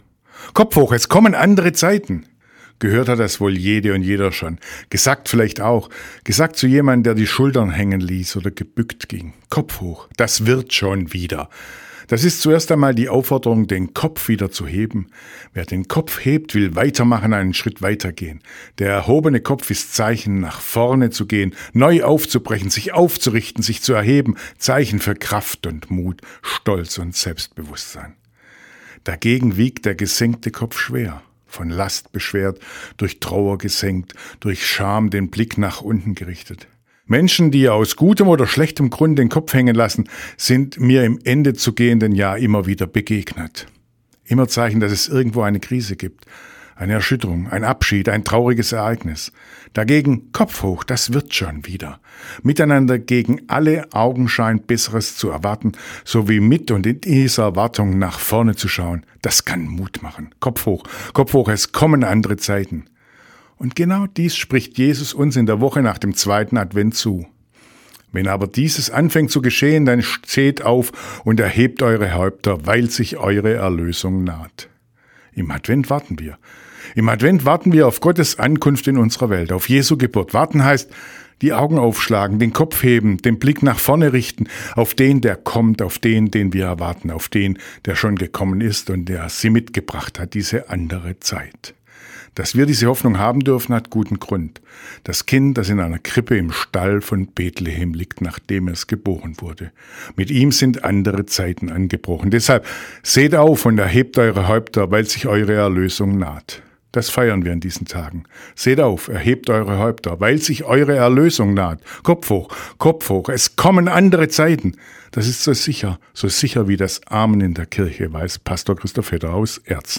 Radioandacht vom 9. Dezember